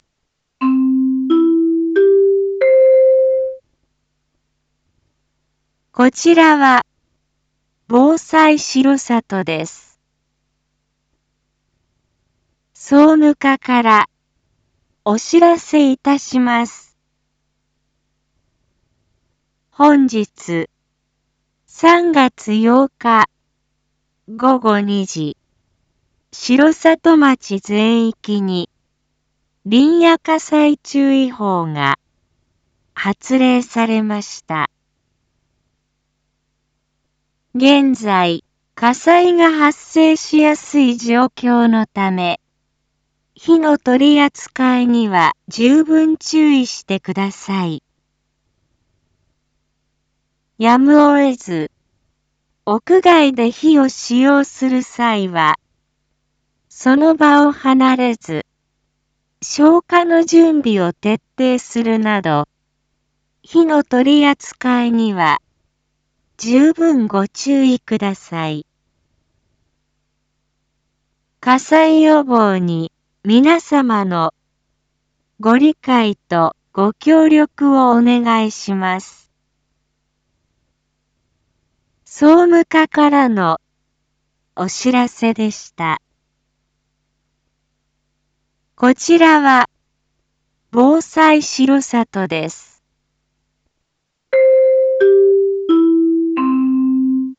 一般放送情報
Back Home 一般放送情報 音声放送 再生 一般放送情報 登録日時：2026-03-08 14:51:41 タイトル：「林野火災注意報」発令中！（火の取扱いに十分ご注意ください） インフォメーション：■ 発令日時 令和8年3月8日 14時00分 ■ 範囲 城里町全域 現在、気象状況が基準に達したため、城里町全域に「林野火災注意報」が発令されました。